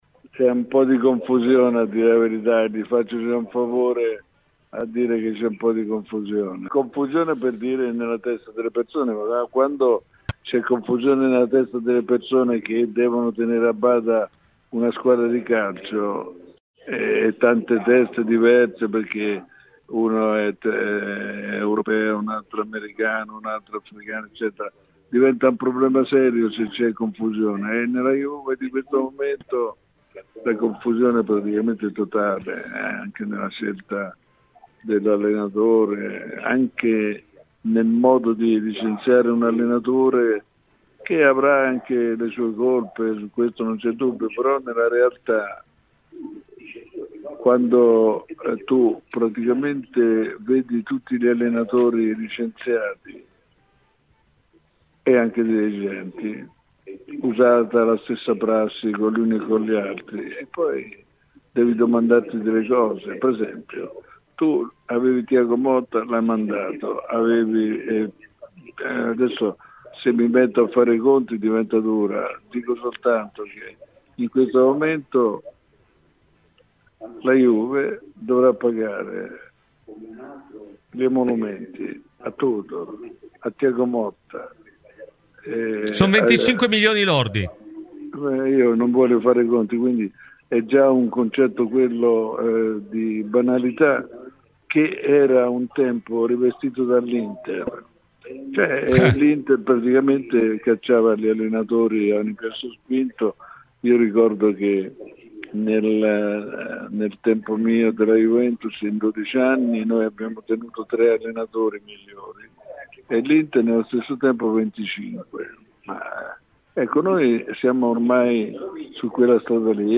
L'ex dg a "Cose di Calcio": "I bianconeri stanno diventando come l'Inter di un tempo. Nei miei anni a Torino cambiarono 25 allenatori, noi tre"